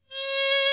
Đàn cò líu
[IMG]àn Cò Líu trong trẻo, chói sáng, biểu đạt những tình cảm đẹp đẽ, cao thượng, vui tươi, sôi nổi... có thể gay gắt, sắc nhọn nhất là ở những âm cao.